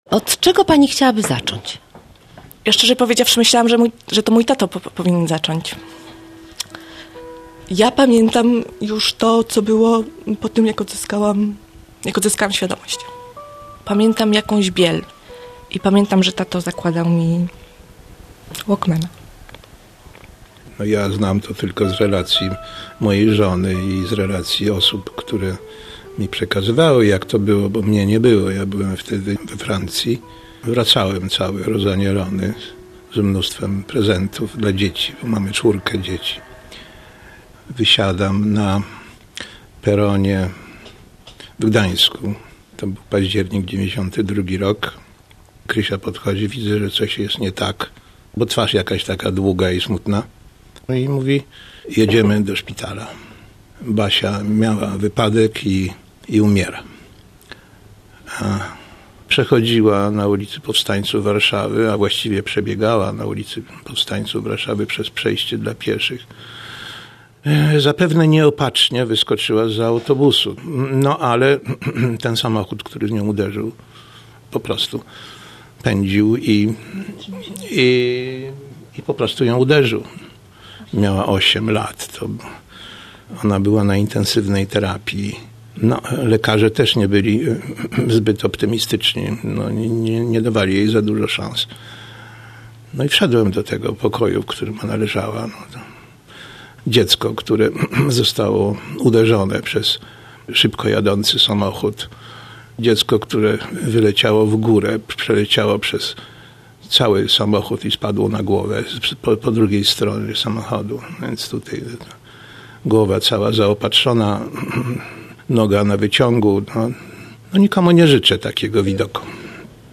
/audio/dok1/powoddozycia.mp3 Tagi: reportaż